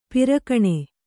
♪ pirakaṇe